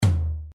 floor-tom.wav